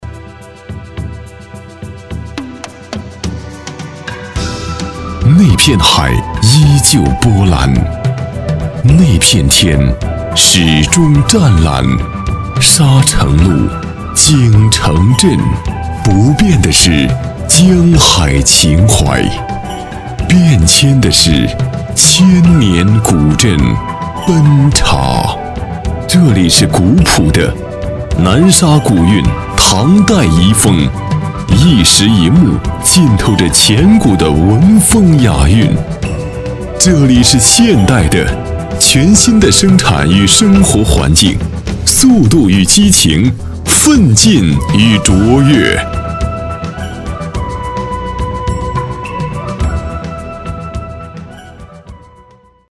男35号